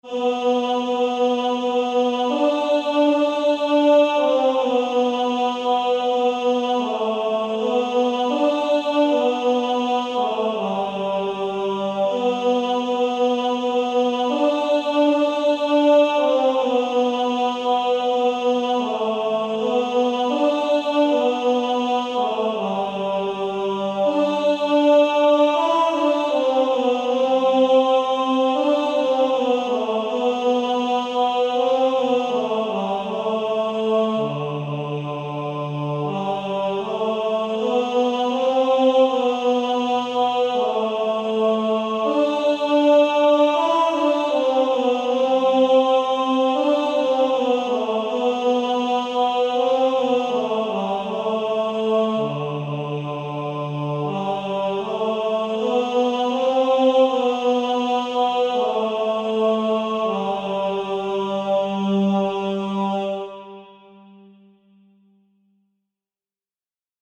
Author: Unknown – French carol